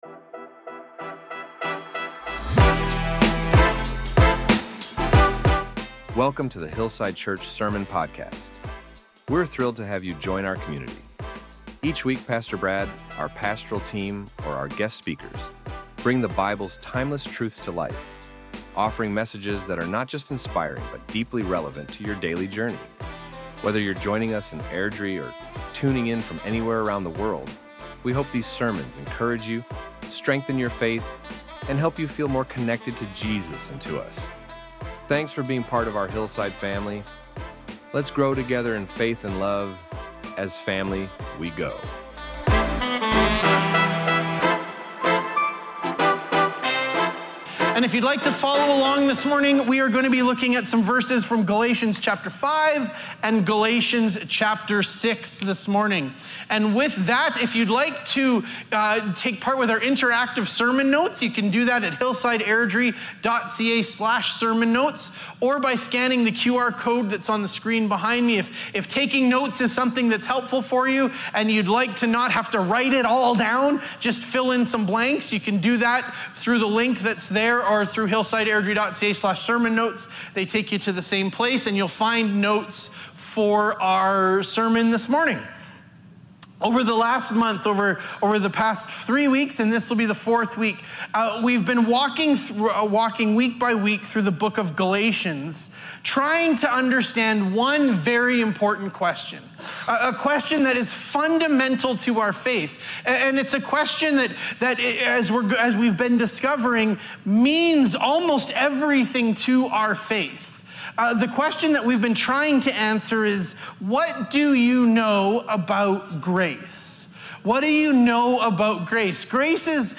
Our Sermons | HILLSIDE CHURCH